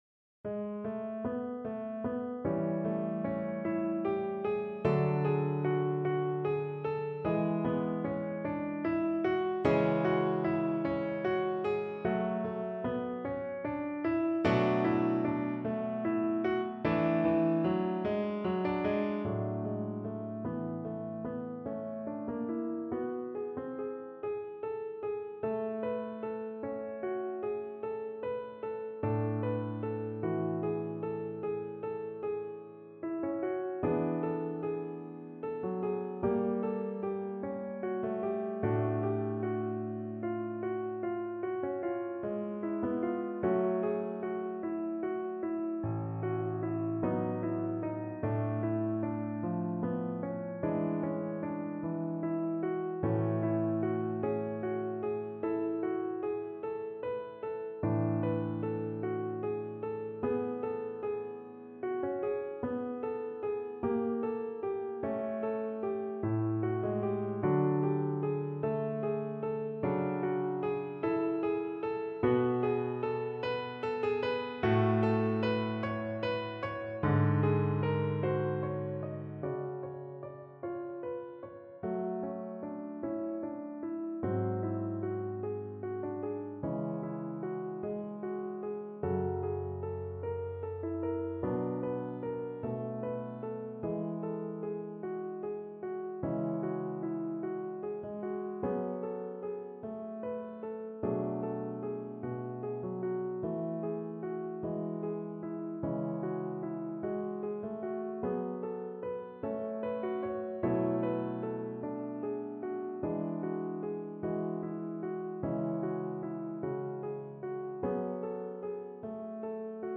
Play (or use space bar on your keyboard) Pause Music Playalong - Piano Accompaniment Playalong Band Accompaniment not yet available transpose reset tempo print settings full screen
Clarinet
4/4 (View more 4/4 Music)
Adagio (=50)
A major (Sounding Pitch) B major (Clarinet in Bb) (View more A major Music for Clarinet )
Classical (View more Classical Clarinet Music)